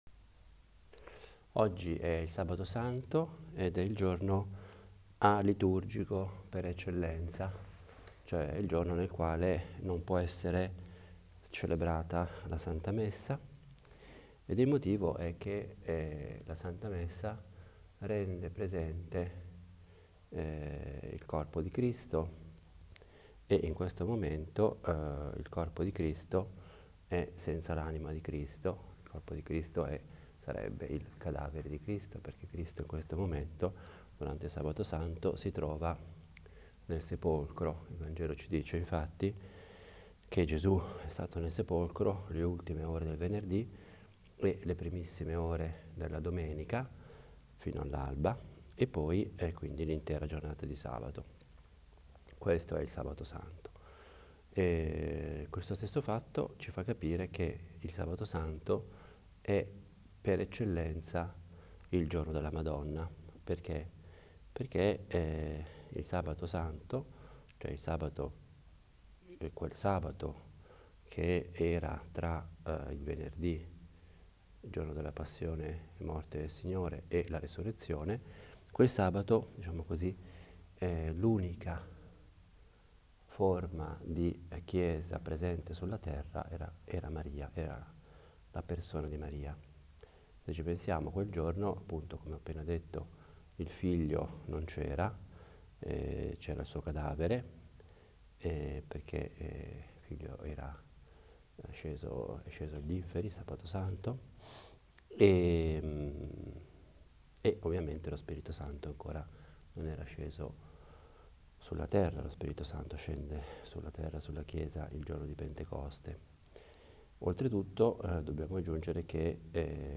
Pausa caffè a Nazareth è una riflessione breve, di otto minuti, sul vangelo della domenica. Una meditazione nella quale cerco di collegare il vangelo con la vita quotidiana e con la nostra prosa più normale: la frase di un giornale, le parole di una canzone. Vorrei avesse il carattere piano, proprio di una conversazione familiare. Io la intendo come il mio dialogo personale – fatto ad alta voce – con Dio e con la Madonna.